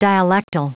Prononciation du mot dialectal en anglais (fichier audio)
Prononciation du mot : dialectal